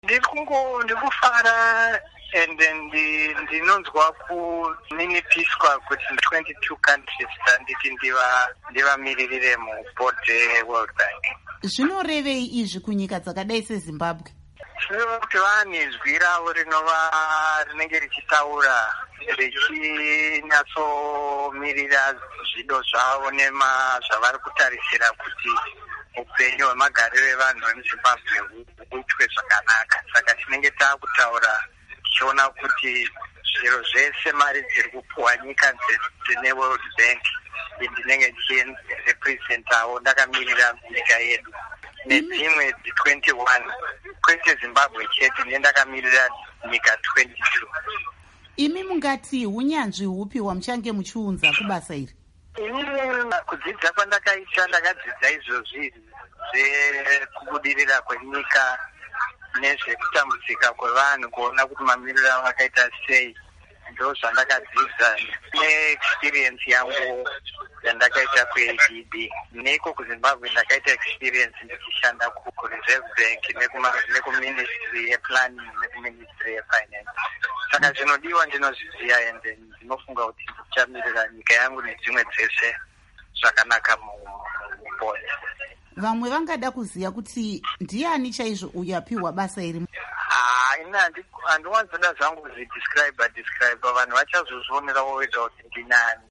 Hurukuro naVaAndrew Ndaamunhu Bvumbe